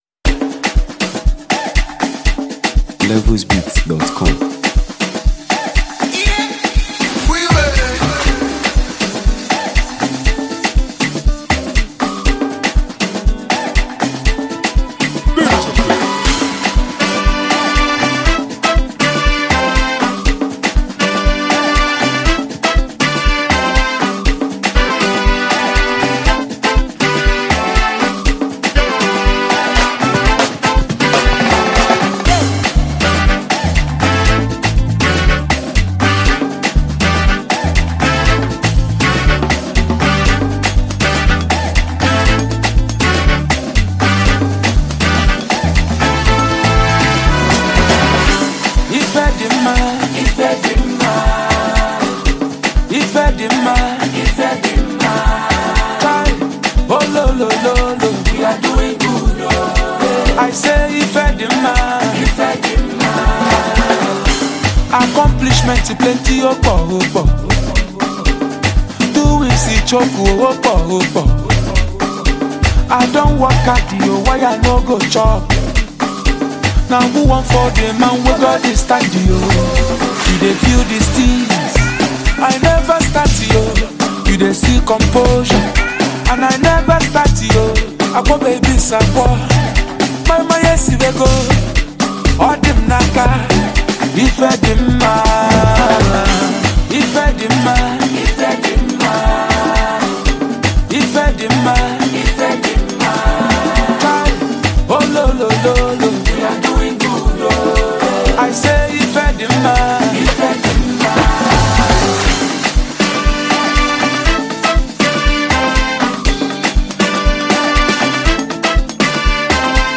Captivating Highlife Anthem
and traditional highlife melodies.